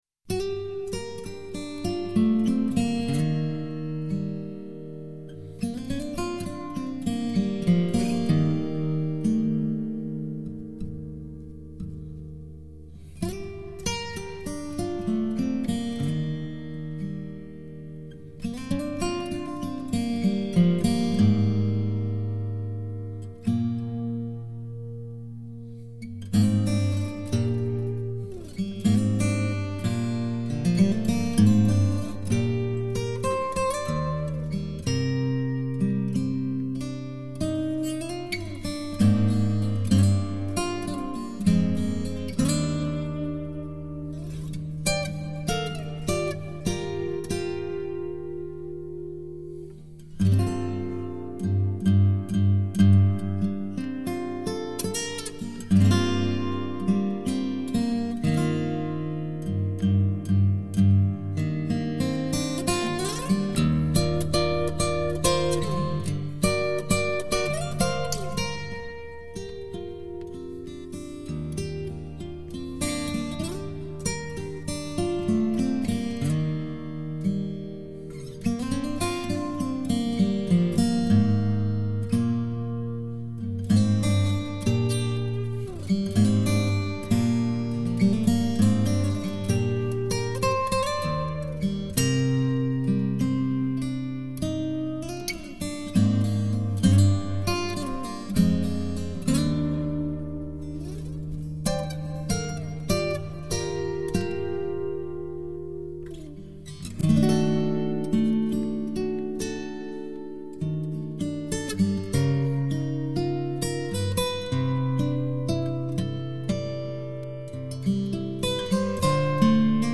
音乐类型：纯音乐